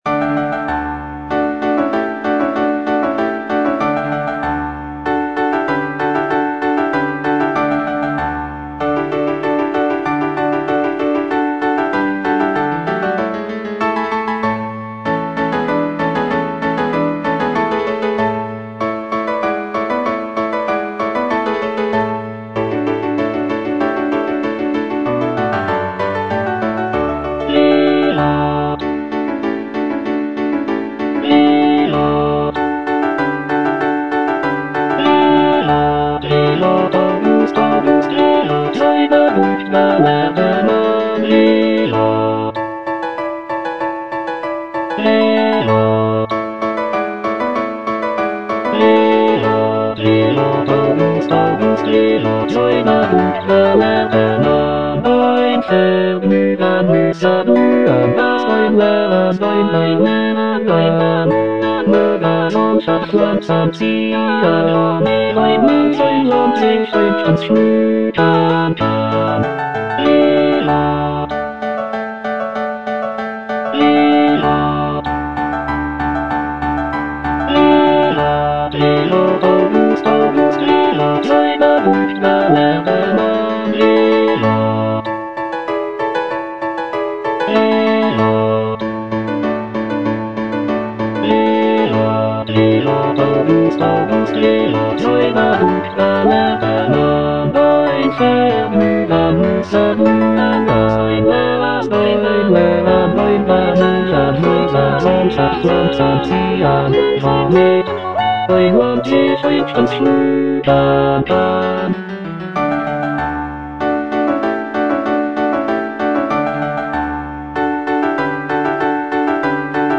The music is lively and celebratory, with intricate counterpoint and virtuosic vocal lines.